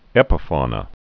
(ĕpə-fônə)